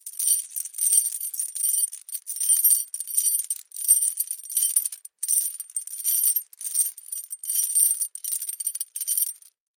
Тихий звон цепи